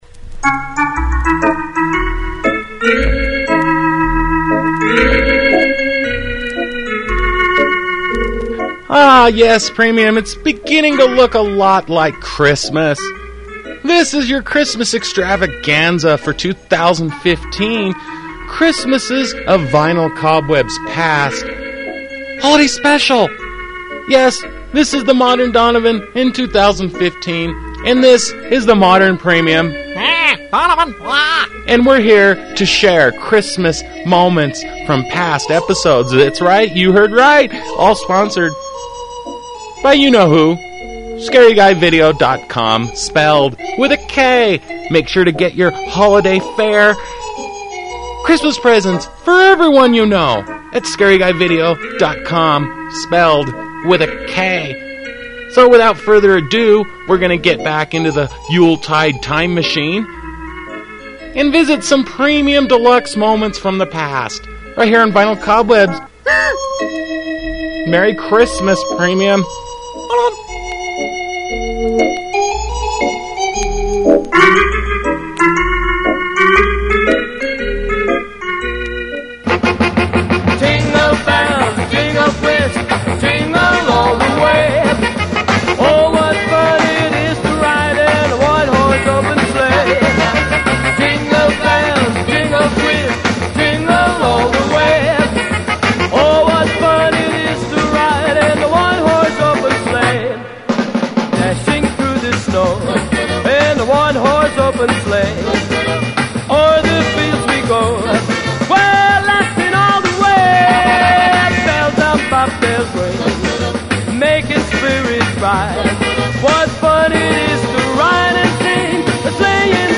Vinyl Cobwebs: Home Of Uncommon Oldies Radio